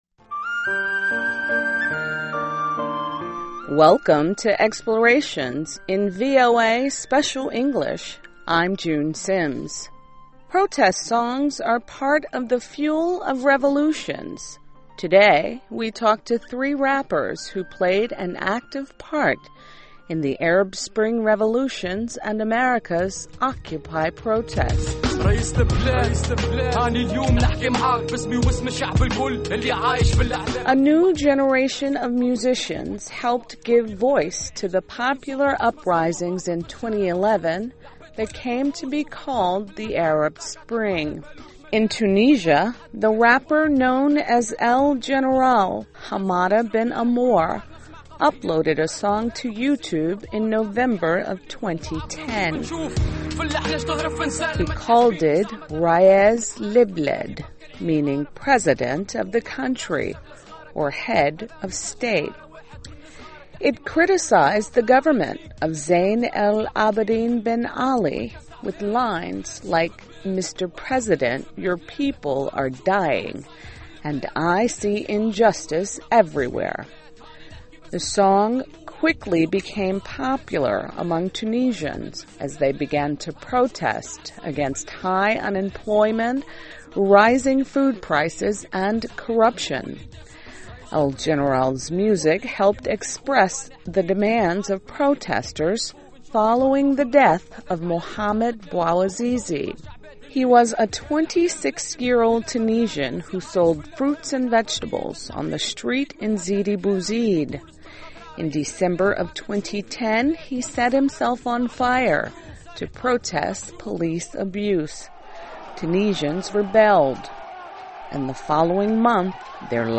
Today, we talk to three rappers who played an active part in the Arab Spring revolutions and America's Occupy protests.